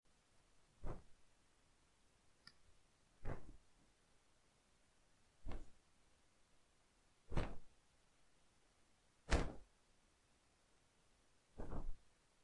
描述：免费声音，效果puerta chirriando
Tag: SoundEffect中 恐怖 FX 基本的 效果 声音